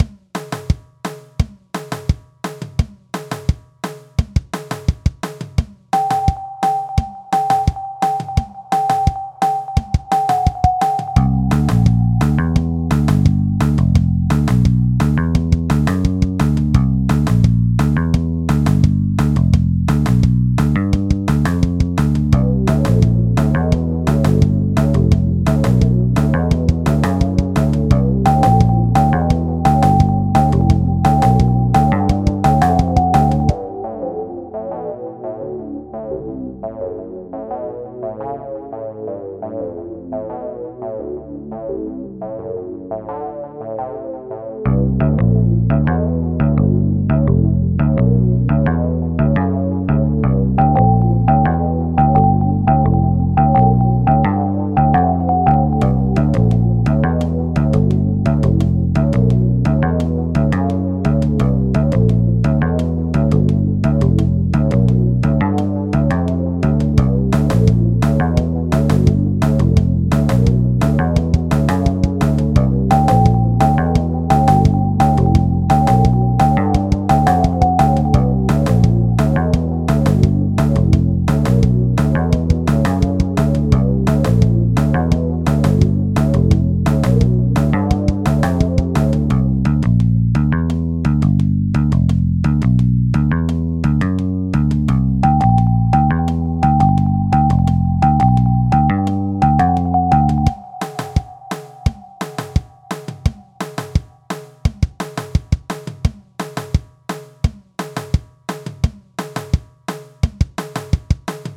The first one is still ears pain inducing, the second one is leagues better